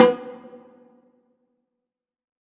WTIMBALE H1P.wav